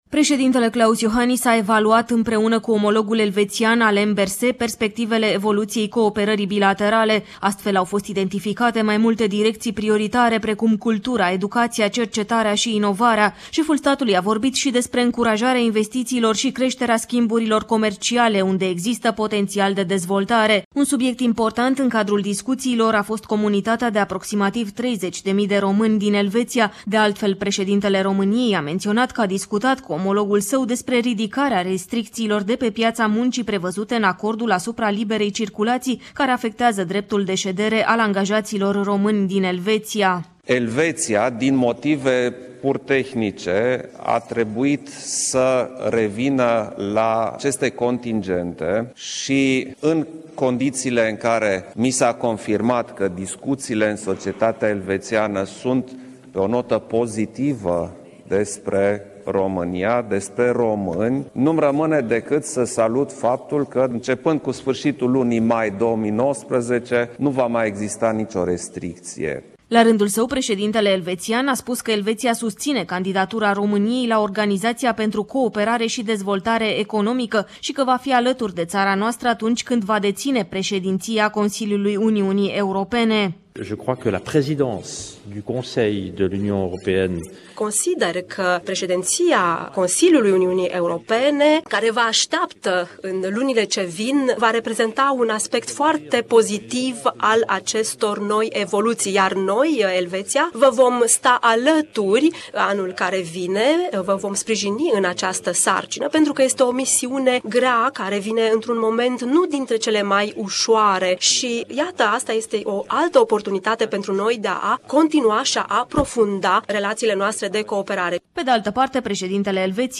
Anunţul a fost făcut în cadrul vizitei efectuate la Bucureşti de preşedintele Confederaţiei Elveţiene, Alain Berset. Detalii în relatarea